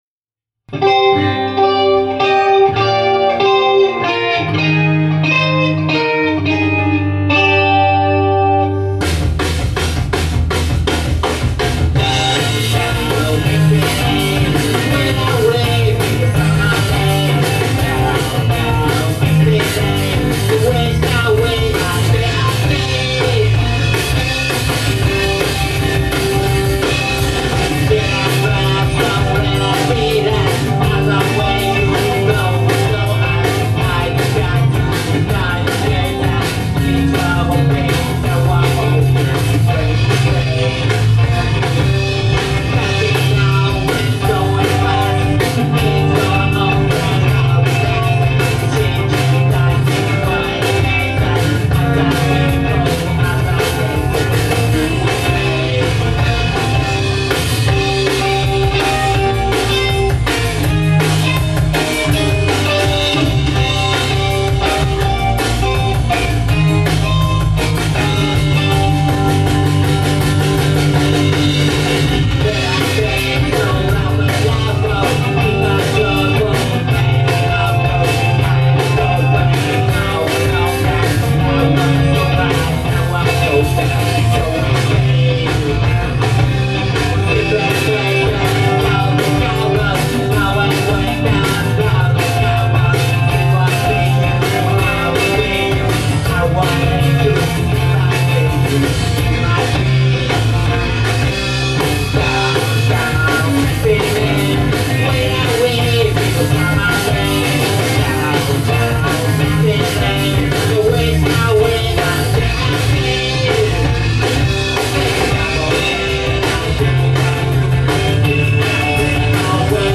Misty Lane @ Studio Rat, June 14 2010